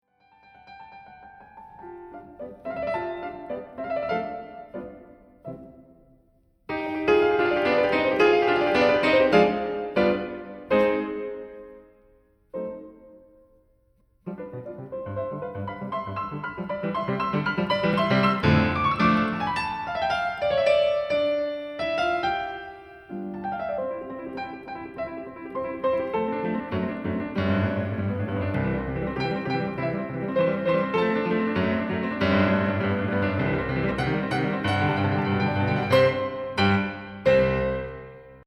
Let’s play from where we are all the way to the end of the exposition: it’s full of energy, little jokes (listen to the sudden quiet chord at 0.12), and I will ask you to listen for a few things:
at 0.06 there is a short, stormy passage.
at 0.14 you can almost imagine a market full of people chatting away.
at 0.18 you can hear the little bird-theme again from the beginning